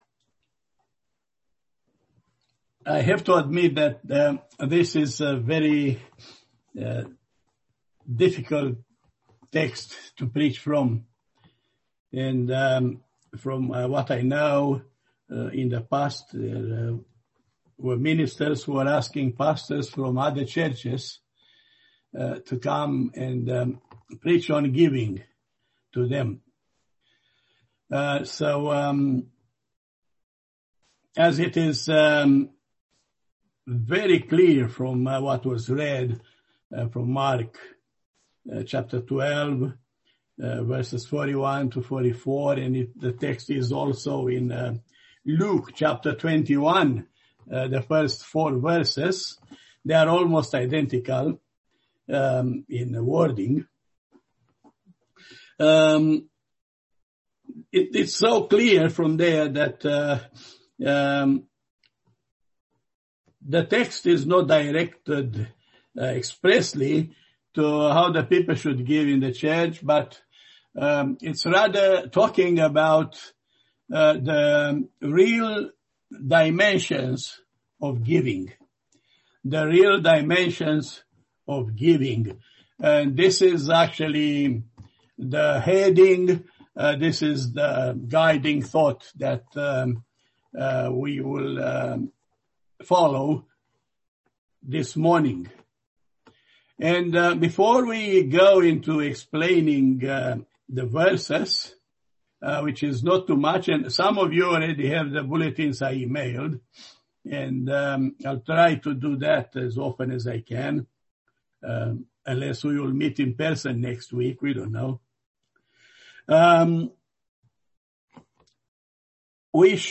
Passage: Mark 12:41-44 Service Type: Sunday Morning